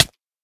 latest / assets / minecraft / sounds / damage / hit2.ogg